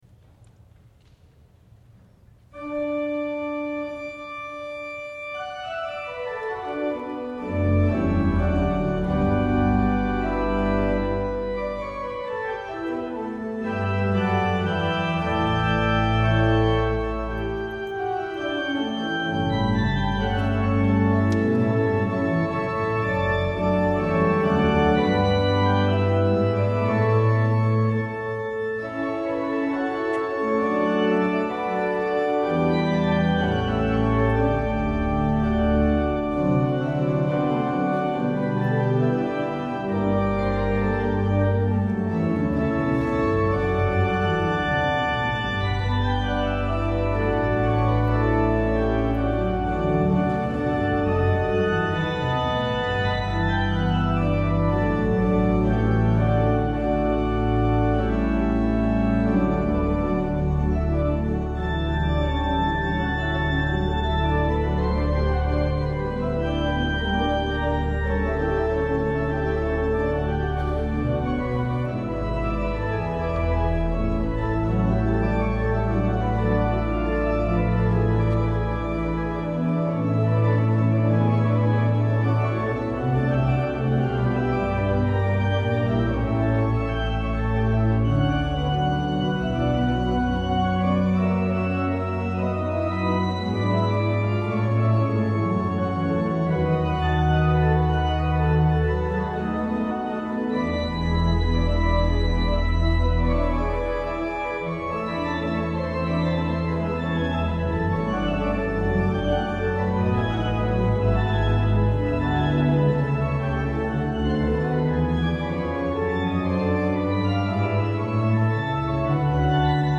à l'orgue de St Agricol d'Avignon